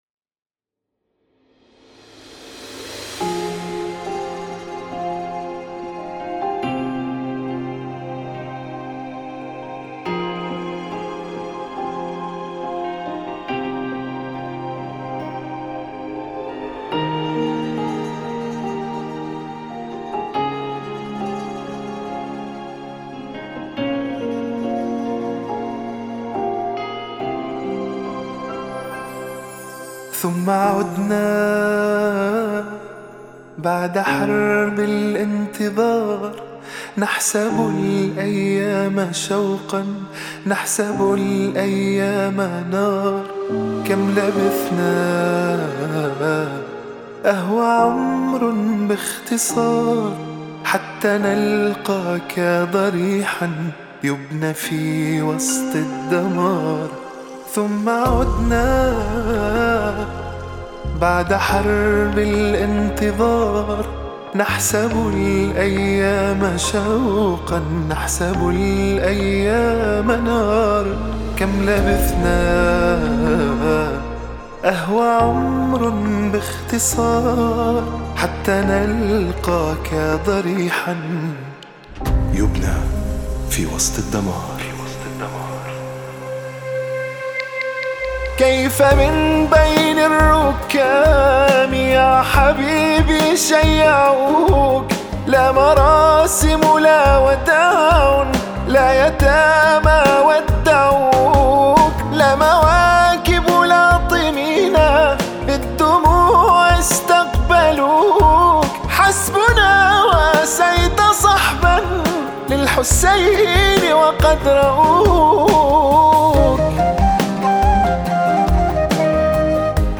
3- مرثية الشهيد :